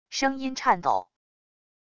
声音颤抖wav音频